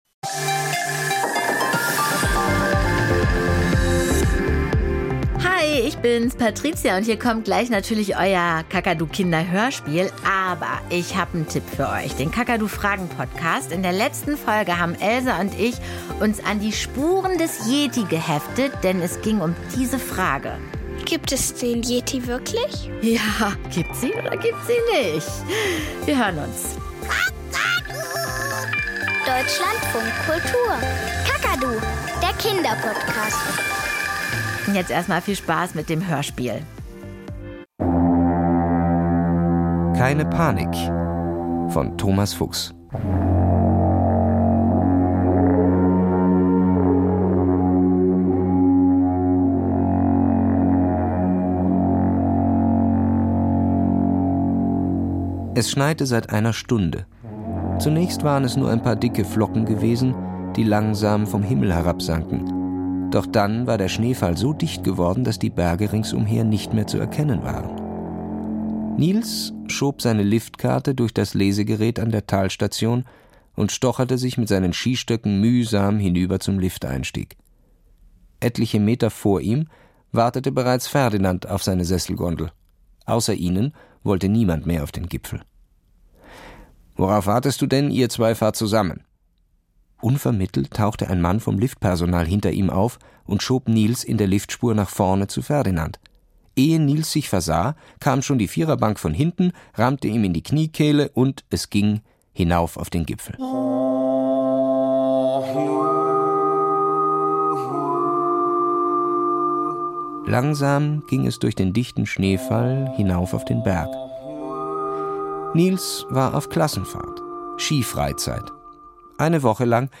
Kinderhörspiel Keine Panik 22:18 Minuten In so einem Lift sitzen Nils und Ferdinand fest.